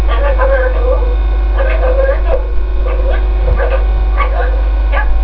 鳥たちのおしゃべり　＜３＞